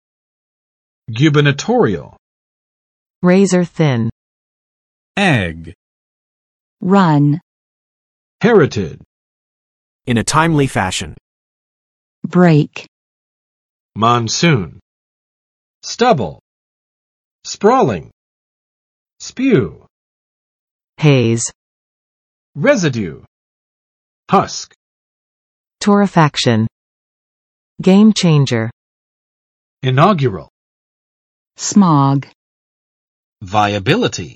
[͵gjubɚnəˋtorɪəl] adj.【美】州长的